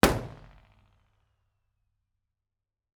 IR_EigenmikePL001F2_processed.wav